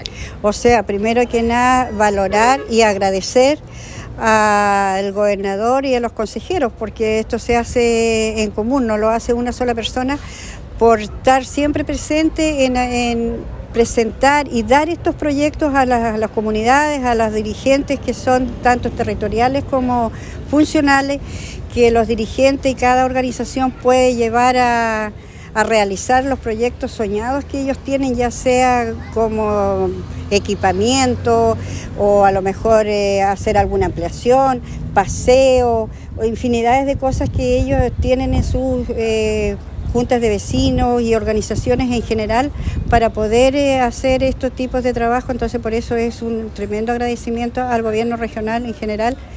Ayer jueves, en la Casa del Folclor de Osorno, se llevó a cabo la Ceremonia de Firma de Convenio del Fondo Comunidad, donde 193 organizaciones territoriales y funcionales de la comuna recibieron recursos destinados a la ejecución de proyectos en diversas áreas, como Seguridad Ciudadana, Adultos Mayores, Fondo Social, entre otras.